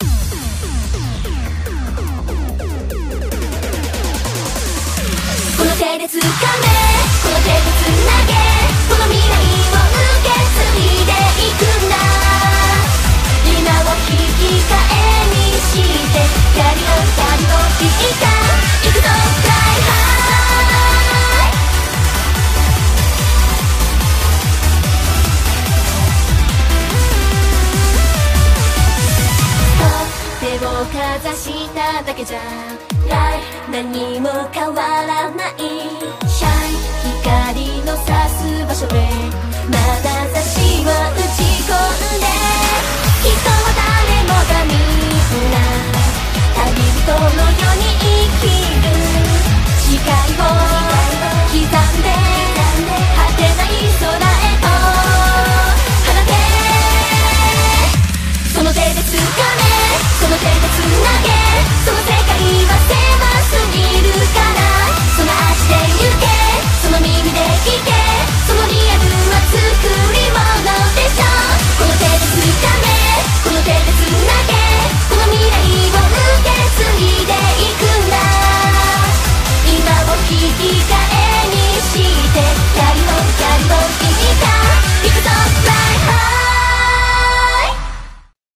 BPM145